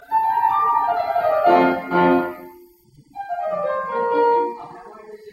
如果他想要两段，就会写一个终止式，然后重新起：